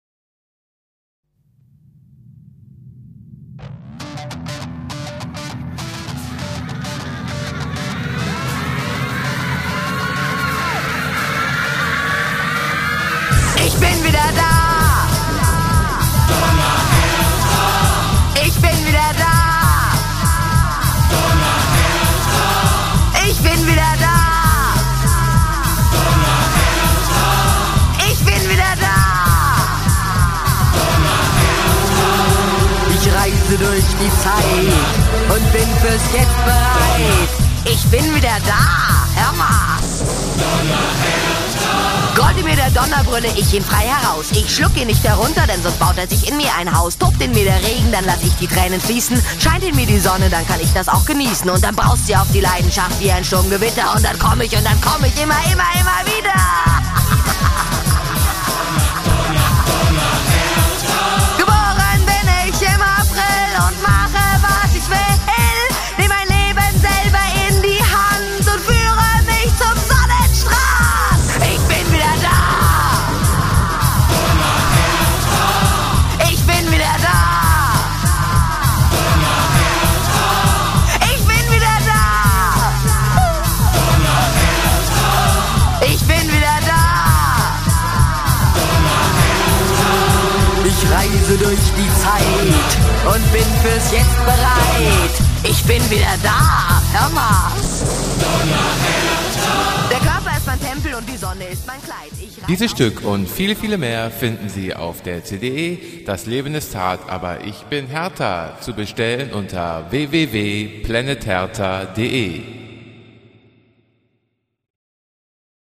in Studio-Fassung